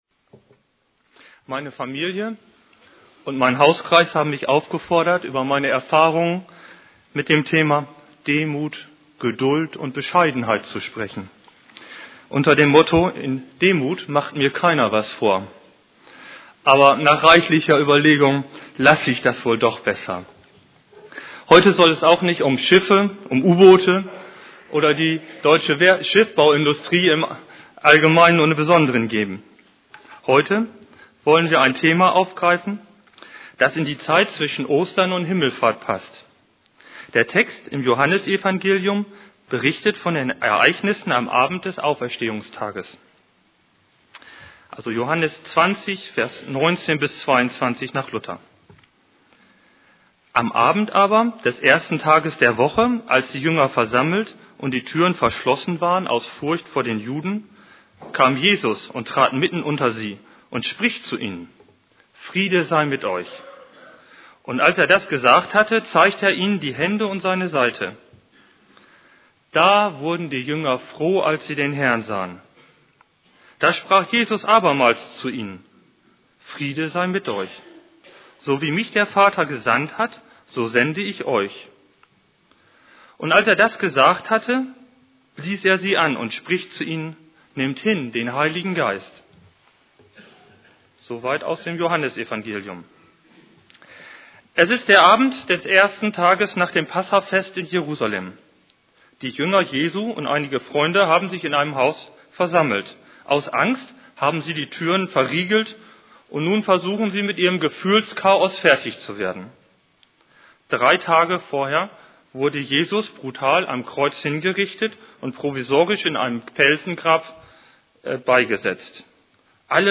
> Übersicht Predigten Da wurden die Jünger froh, dass sie den Herrn sahen Predigt vom 18.